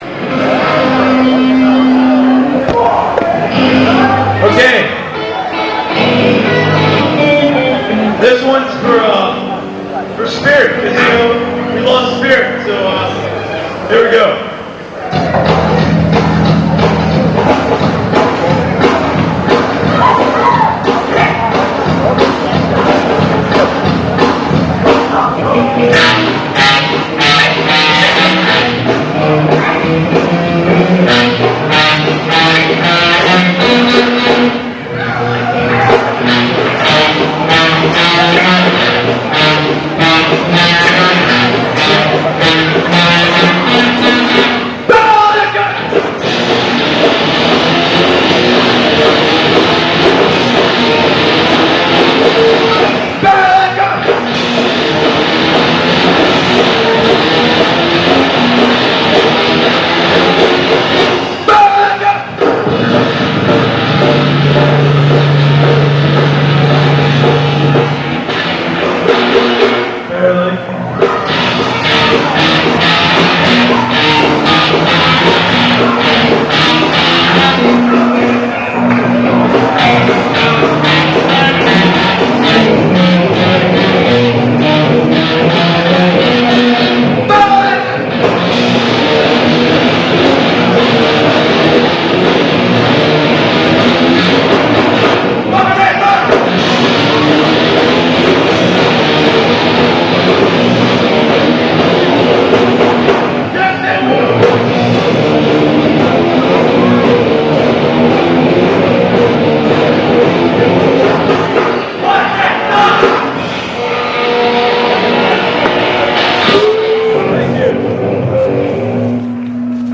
May 23, 1996 - Dunwoody High School's "Battle of the Bands"
The Bearlicker Fight Song - The infamous "Bearlicker Fight Song!" It's not hard to remember the words to this catchy ditty. Listen for the "WATERSGRAVE SUCKS!" at the end.